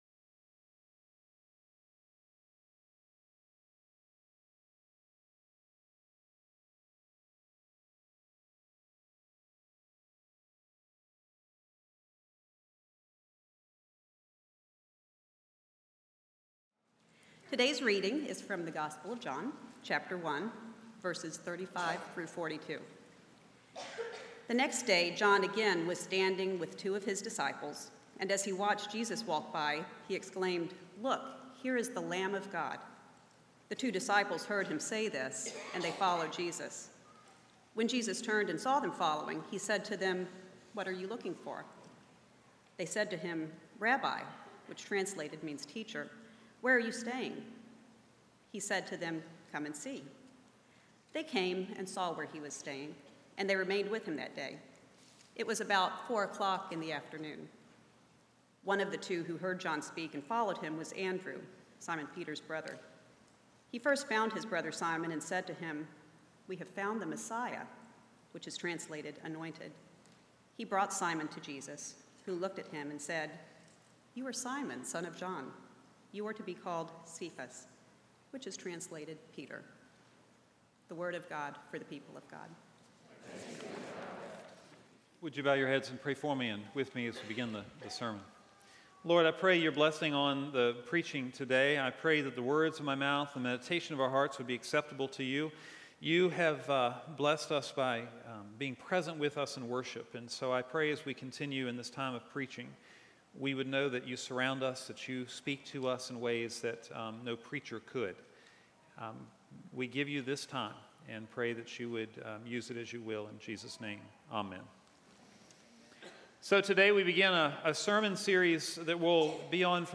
Scripture: John 1:35-42, This is the first sermon of the sermon series Six Decisions that will Change Your Life.
sermon2-10-13.mp3